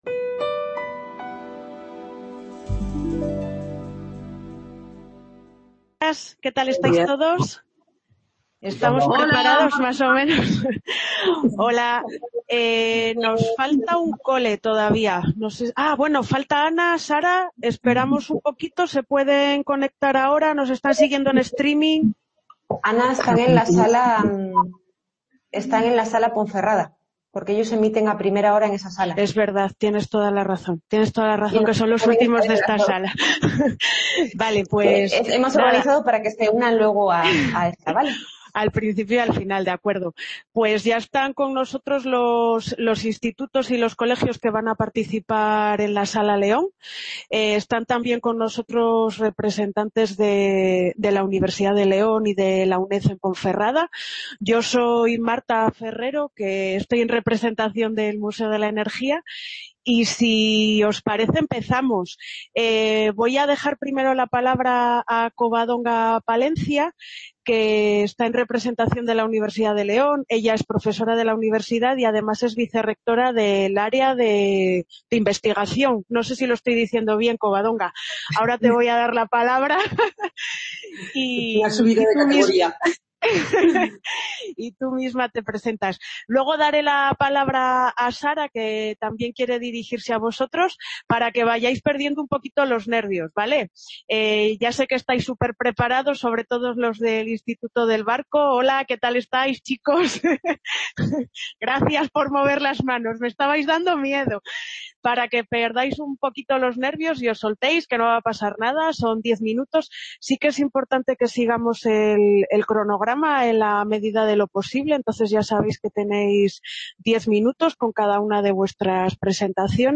Description Congreso organizado por La Fábrica de Luz. Museo de la Energía junto con la ULE, la UNED y en colaboración con FECYT que se desarrolla en 3 salas CA Ponferrada - 2 Edición Congreso de Jóvenes Expertos.